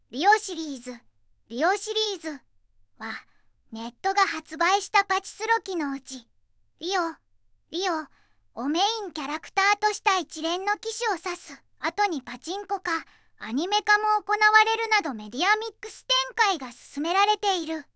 VOICEVOX: ずんだもんを利用しています